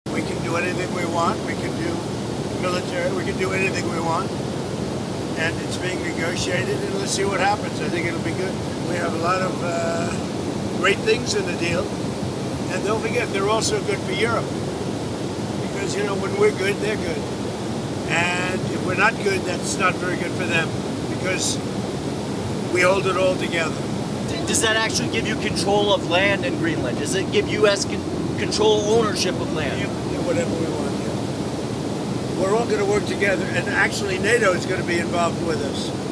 Într-un dialog cu jurnaliștii aflați la bordul Air Force One, Donald Trump a vorbit despre discuțiile privind Groenlanda.